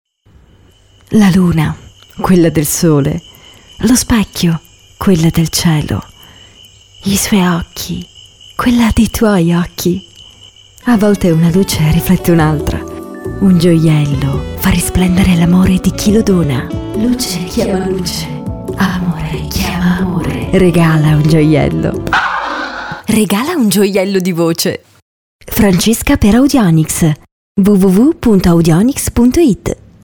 Actor-speaker
Voci specializzate in parti recitate.